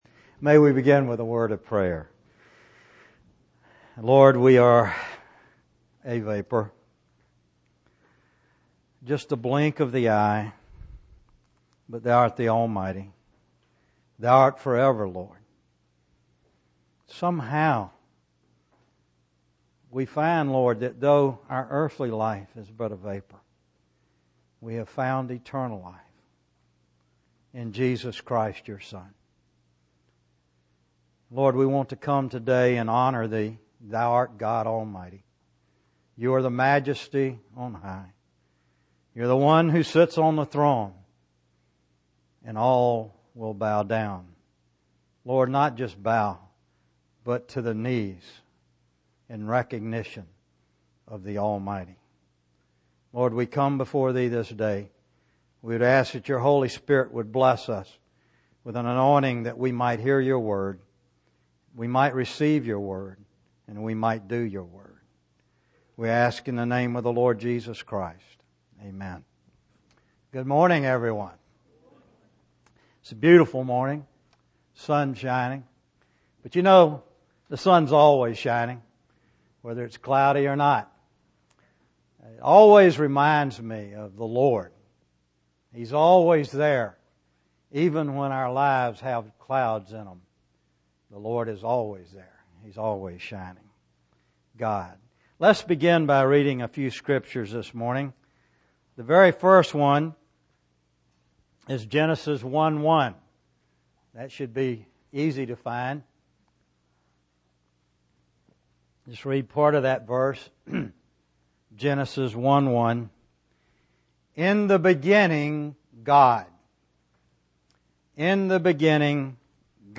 A collection of Christ focused messages published by the Christian Testimony Ministry in Richmond, VA.
We apologize for the poor quality audio